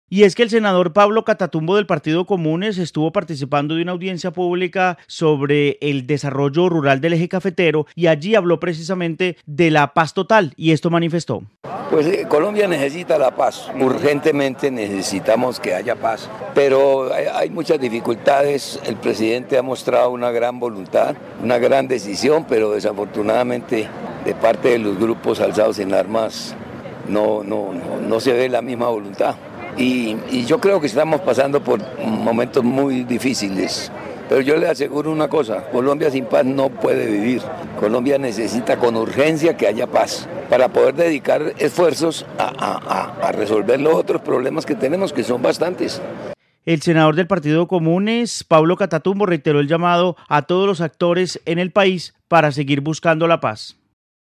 El senador habló desde Armenia sobre la paz total del Gobierno.
Informe Catatumbo